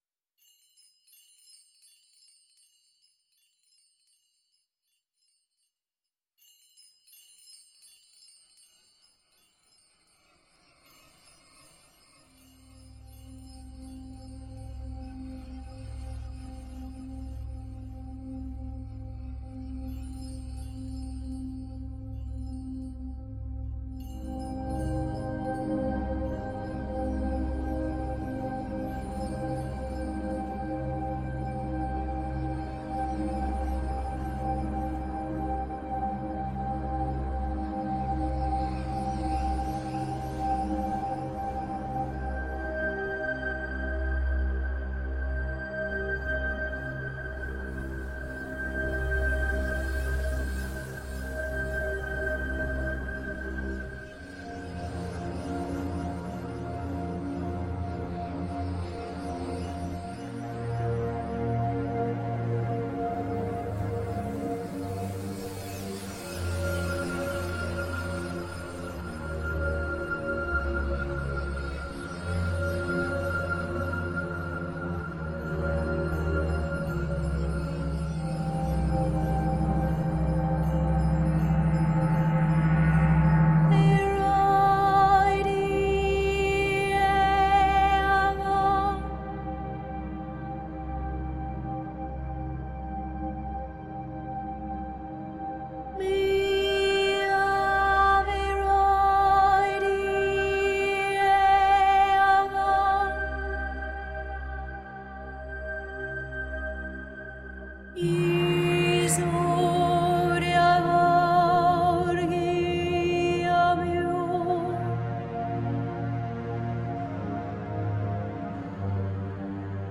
Vivid world-electronica grooves.